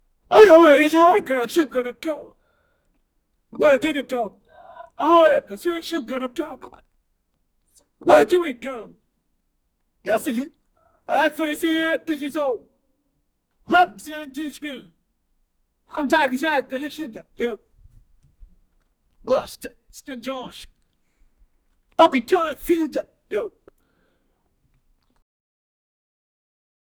a-very-angry-person-speak-7x4jdtr7.wav